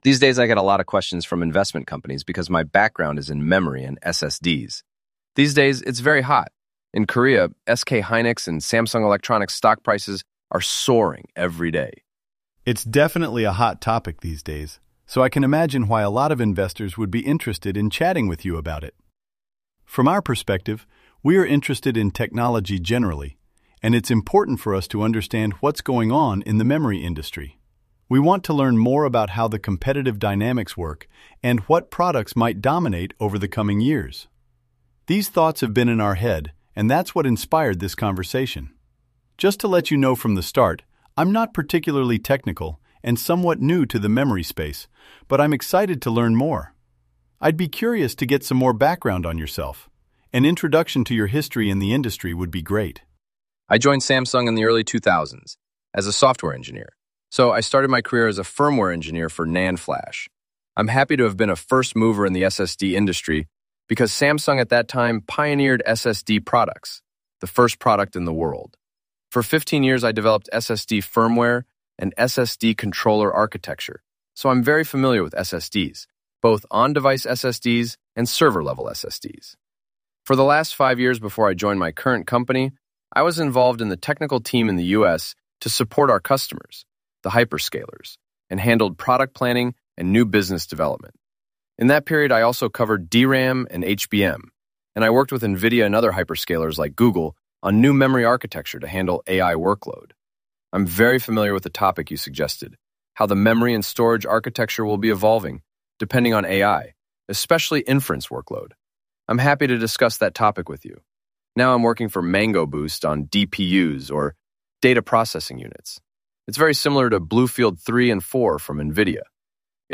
In Practise Interviews